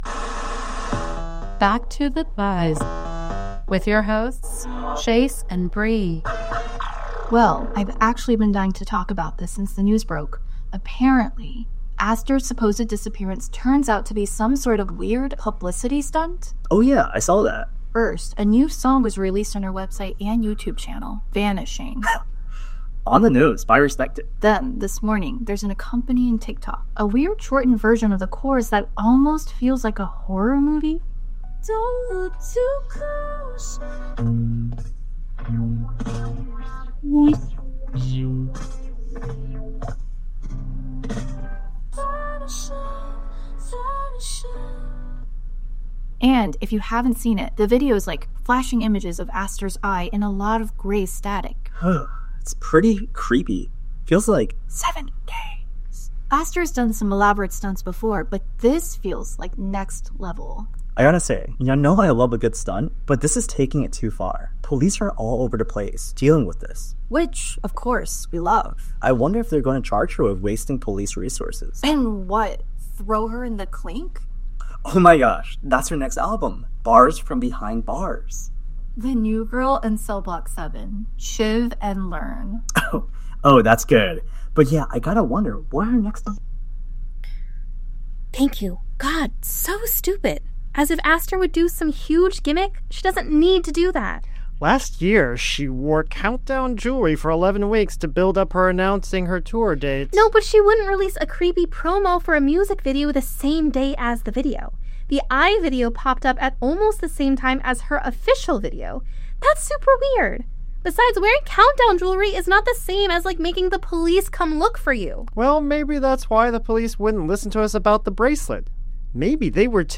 Each episode blends immersive audio drama with original songs containing clues.